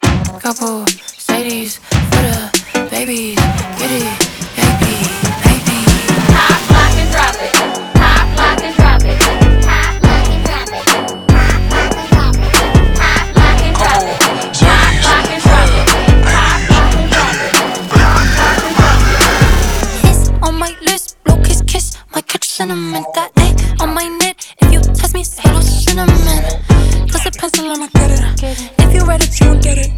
# Альтернативный рэп